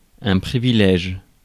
Ääntäminen
Ääntäminen France: IPA: [pʁi.vi.lɛʒ] Haettu sana löytyi näillä lähdekielillä: ranska Käännös Ääninäyte Substantiivit 1. privilege US 2. perquisite Suku: m .